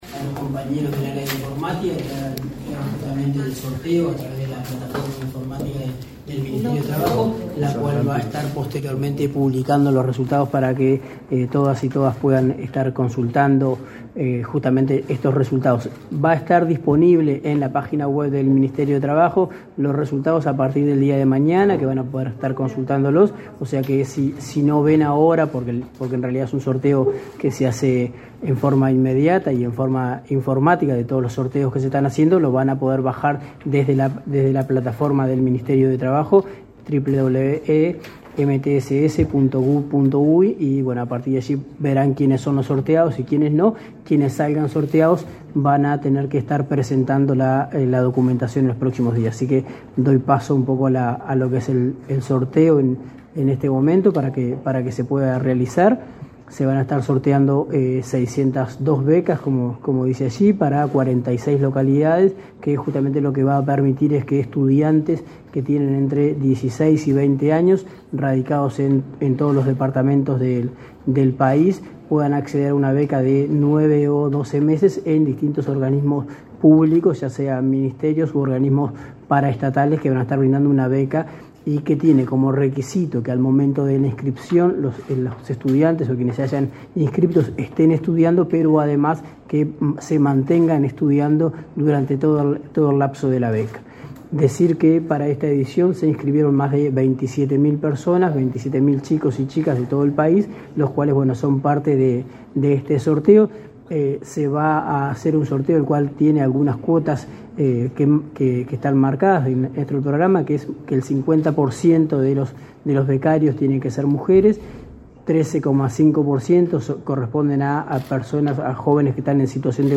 Declaraciones a la prensa del director nacional de Empleo, Daniel Pérez
Tras el sorteo del programa Yo Estudio y Trabajo, el director nacional de Empleo, Daniel Pérez, realizó declaraciones a la prensa este 7 de noviembre.